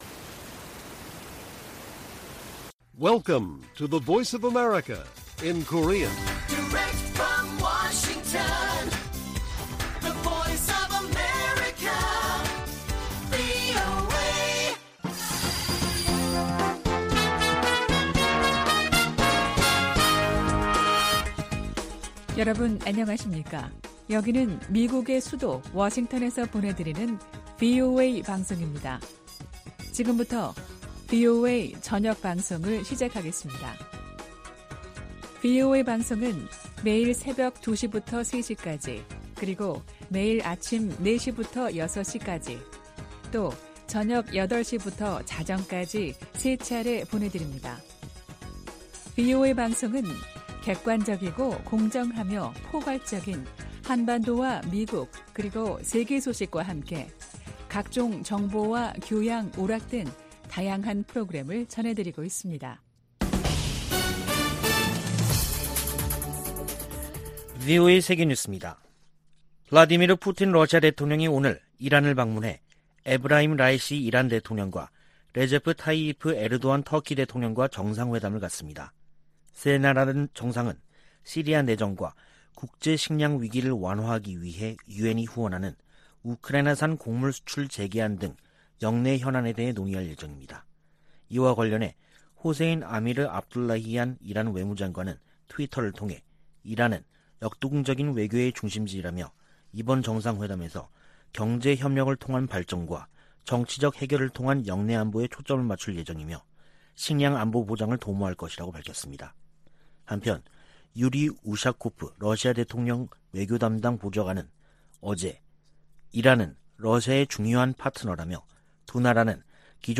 VOA 한국어 간판 뉴스 프로그램 '뉴스 투데이', 2022년 7월 19일 1부 방송입니다. 주한미군은 미한 공동 안보 이익을 방어하기 위해 필요하다는 입장을 미 국방부가 확인했습니다. 한국을 방문한 미 재무장관은 탄력성 있는 공급망 구축을 위한 협력을 강조하며 중국의 시장 지배적 지위를 막아야 한다고 말했습니다. 미 국무부가 북한의 인권 상황은 대량살상무기 만큼이나 우려스러운 부분이라고 지적했습니다.